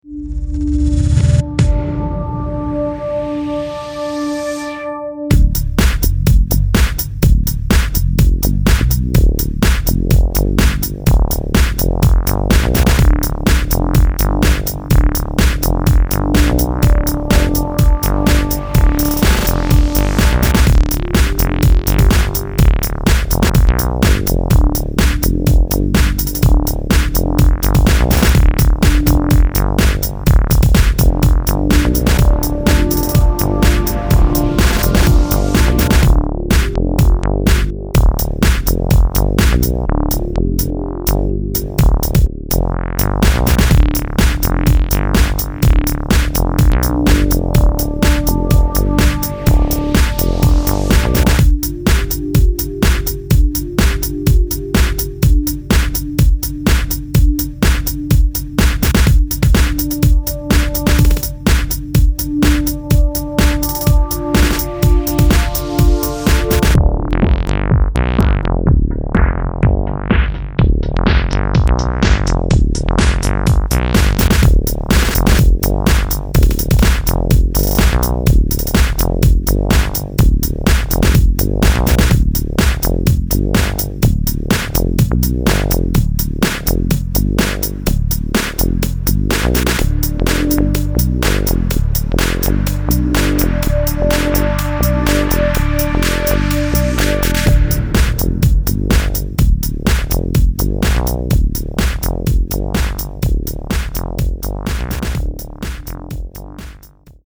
electro-dance